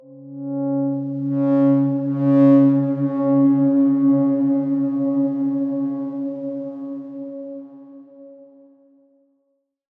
X_Darkswarm-C#3-ff.wav